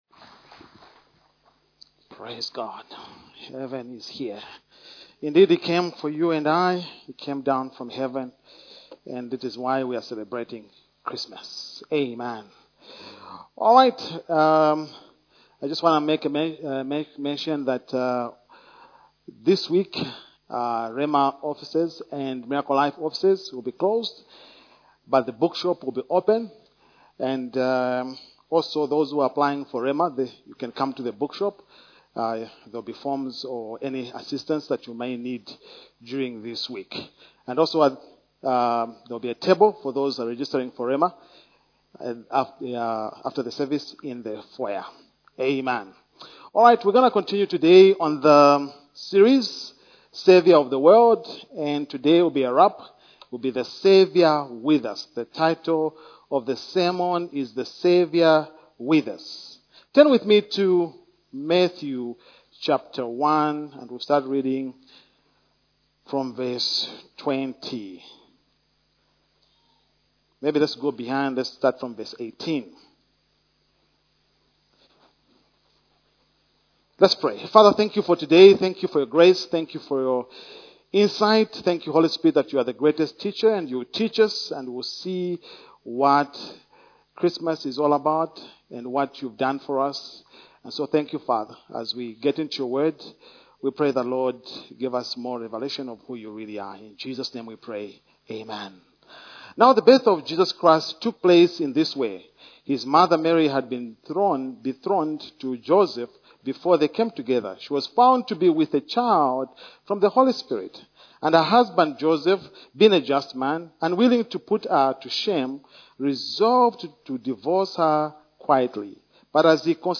A message from the series "Saviour of the World."